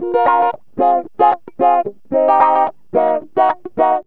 GTR 20A#M110.wav